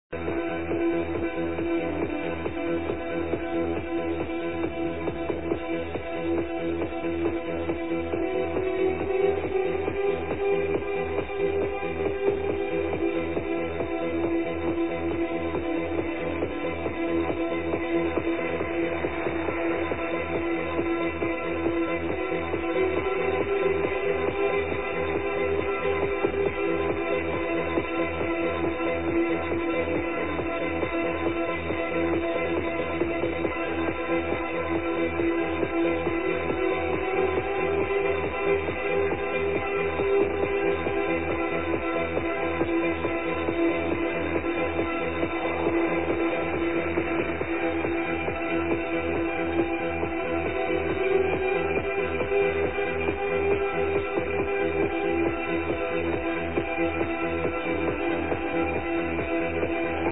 It's one of the most beautiful pieces of dream trance I've ever heard.
It has the same kind of atmosphere as Man in the Moon, but it's much more layered with melodies (piano, soundscapes). It has definitely the typical Robert Miles sound.
It's VERY melodic and builds up to a climax at around 6min. The voices used in this song are a bit different as in Man in the Moon, more in harmony with the soundscapes.
It's still the best dream trance I've ever heard. The female voices are so beautiful